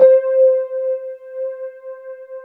WETRD  C4 -R.wav